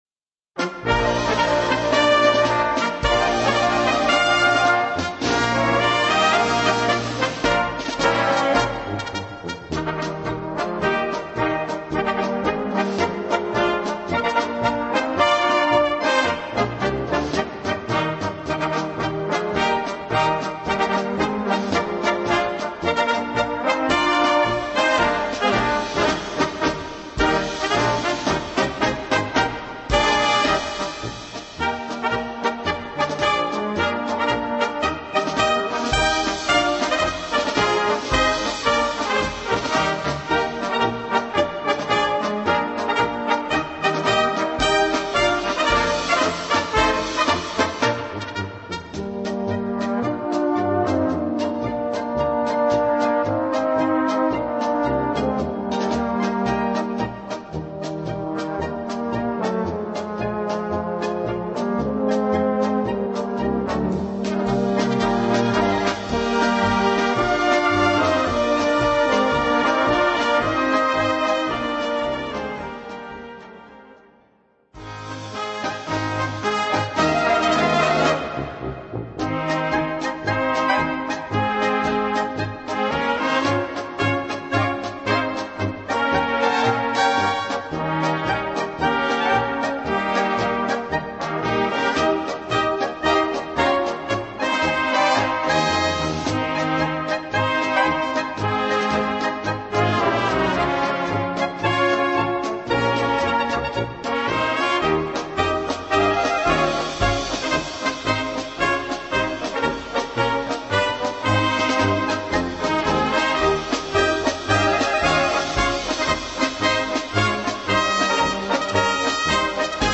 Gattung: Böhmische Polkas
Besetzung: Blasorchester